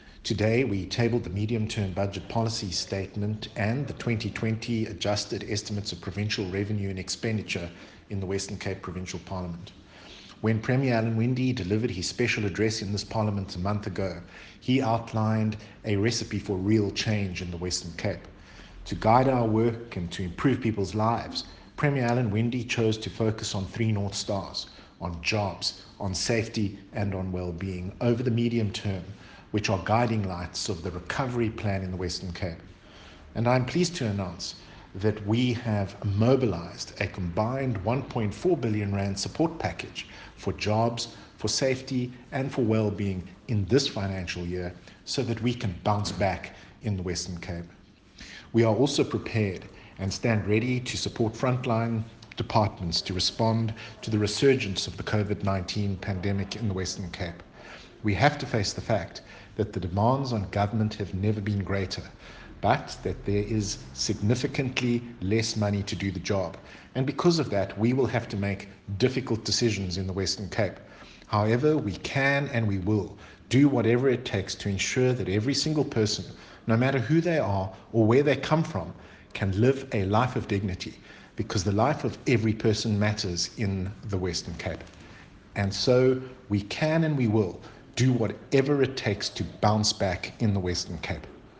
Listen to Minister Maynier's  summary of MTBPS: